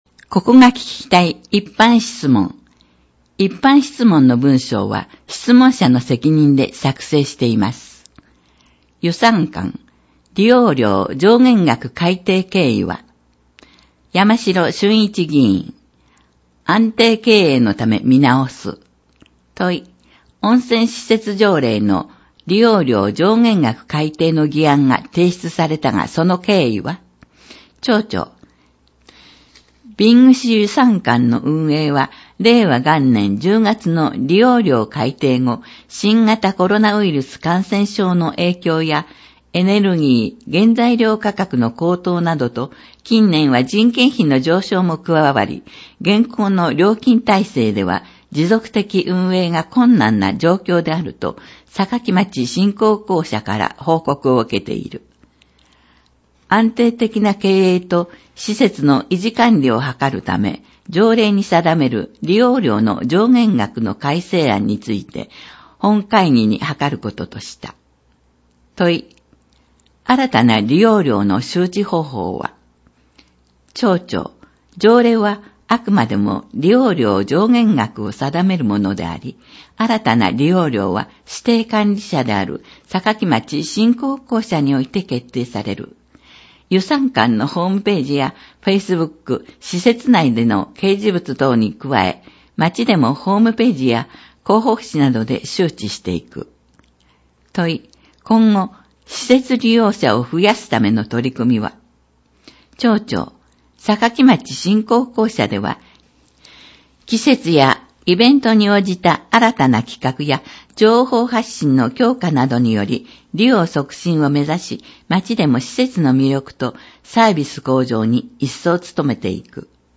広報音訳版ダウンロード（制作：おとわの会）